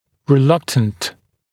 [rɪ’lʌktənt][ри’лактэнт]делающий что-л. с большой неохотой, по принуждению; сопротивляющийся